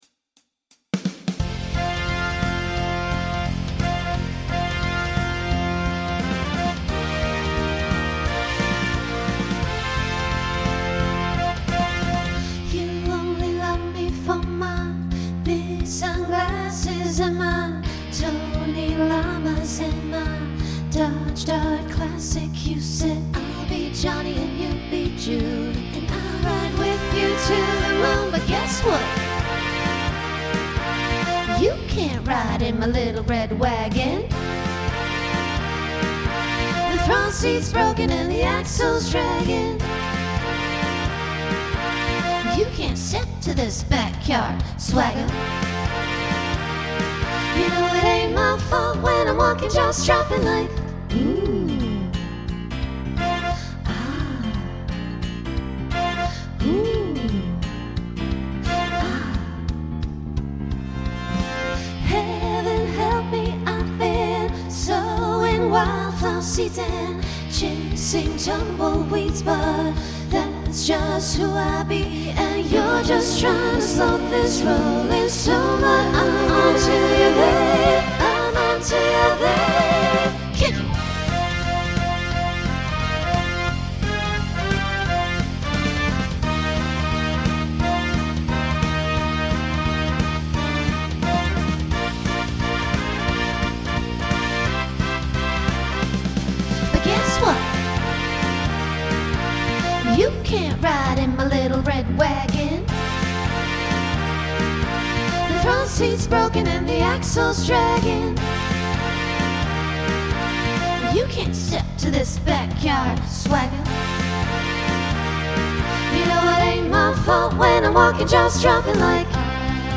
Genre Country Instrumental combo
Voicing SSA